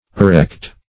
Arrect \Ar*rect"\, v. t.